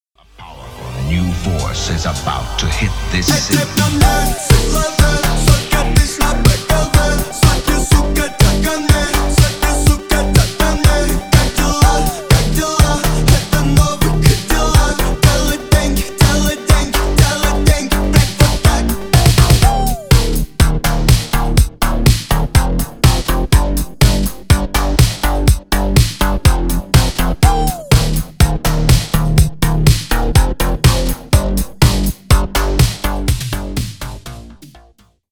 • Качество: 320 kbps, Stereo
Ремикс
Рэп и Хип Хоп